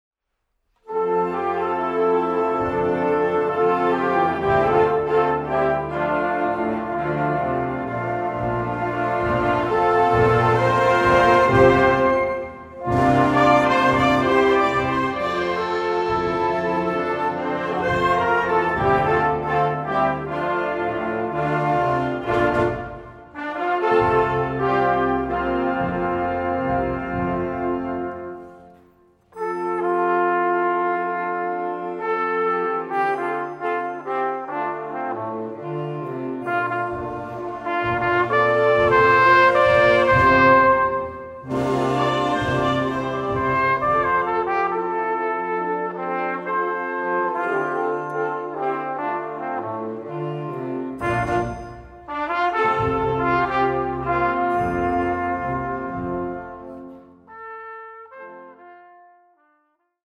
Music for Symphonic Wind Orchestra